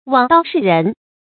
枉道事人 注音： ㄨㄤˇ ㄉㄠˋ ㄕㄧˋ ㄖㄣˊ 讀音讀法： 意思解釋： 枉：違背；道：正道；事：侍奉。